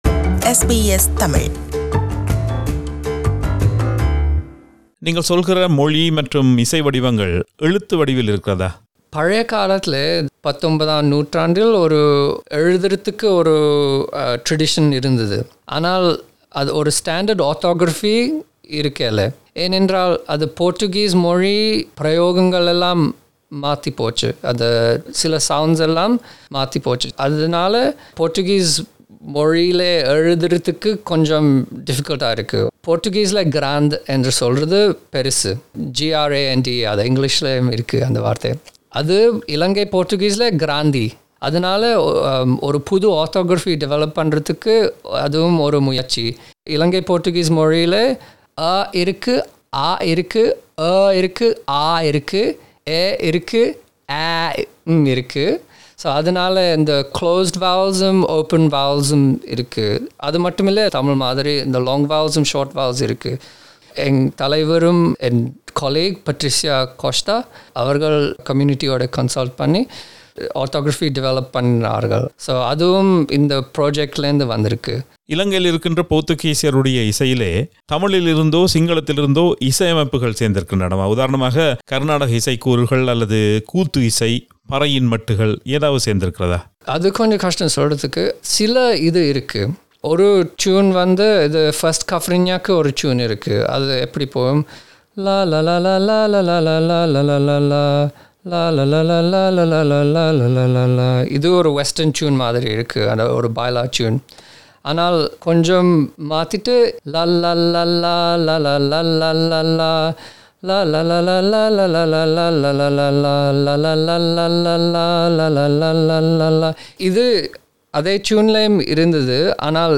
இரு பகுதிகளாக பதிவாகியுள்ள நேர்காணலின் இறுதிப் பாகத்தில்